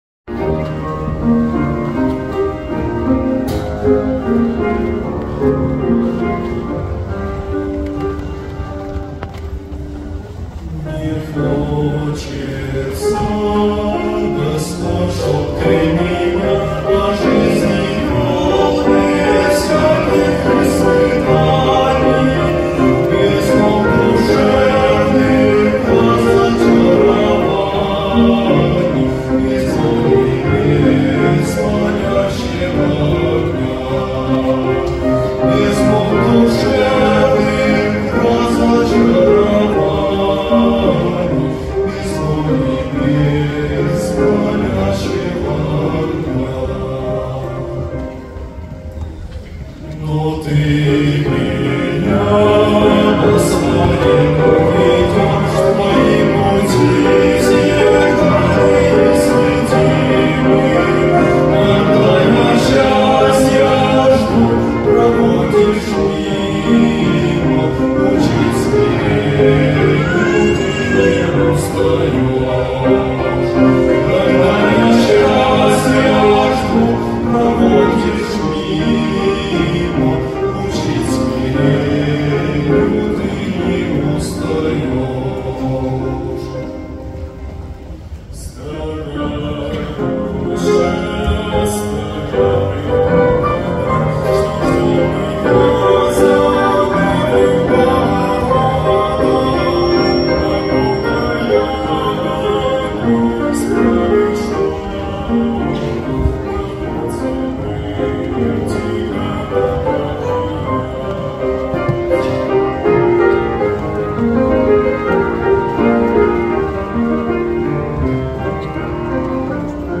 112 просмотров 84 прослушивания 4 скачивания BPM: 75